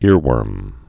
(îrwûrm)